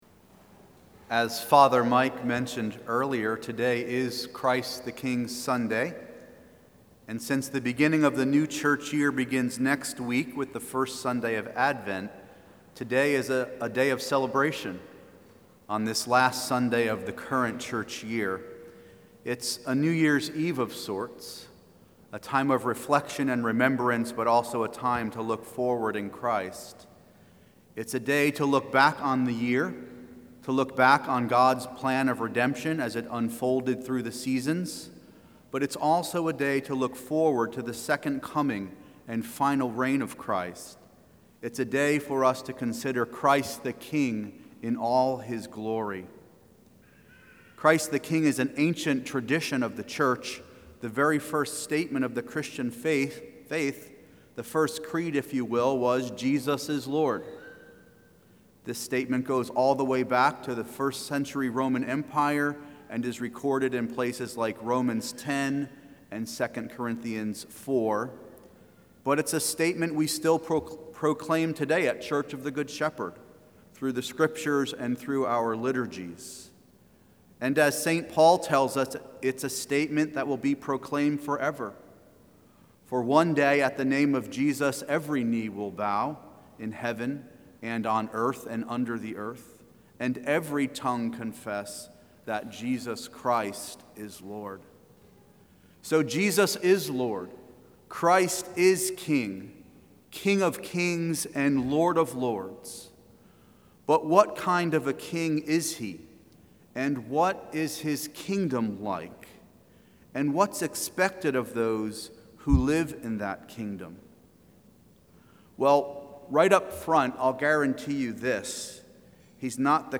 Download Download Ordinary Time 2024 Current Sermon Not the King You Were Expecting